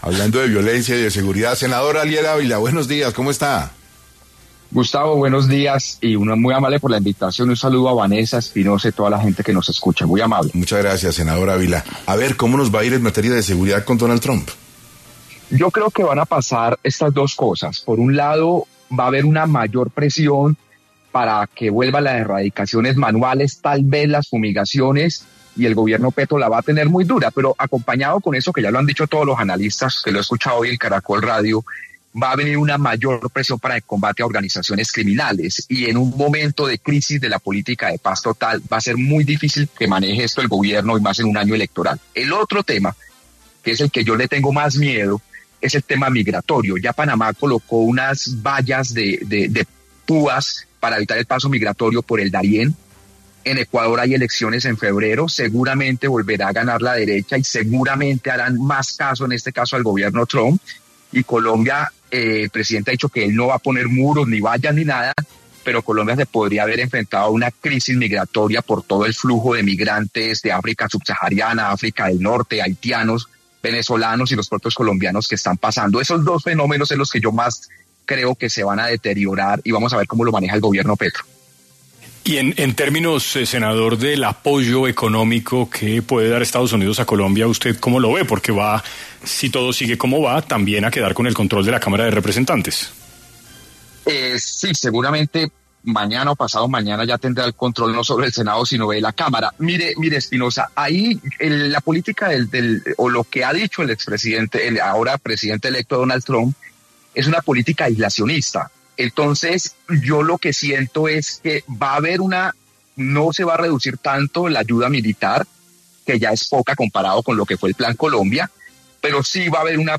A través de los micrófonos de Caracol Radio, estuvo Ariel Ávila, senador colombiano, y reflexionó acerca de las consecuencias que podría surgir en el país, con la llegada de Donald Trump a la Casa Blanca.